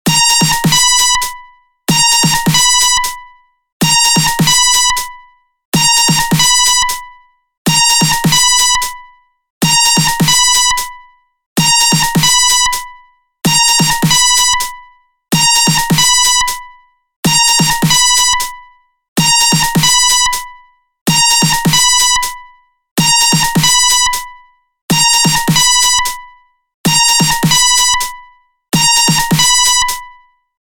• Качество: 192, Stereo
громкие
без слов
звонкие
Мелодия для будильника, что сразу встанешь :)